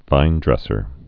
(vīndrĕsər)